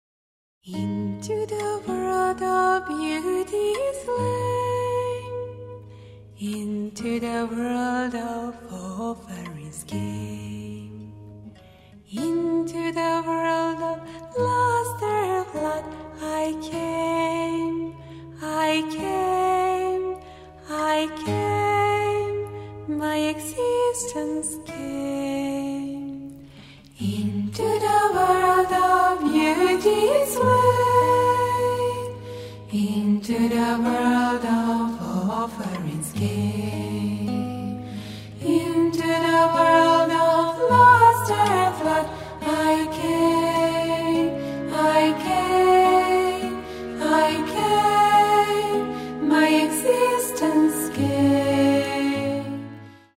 გოგოების ანსამბლი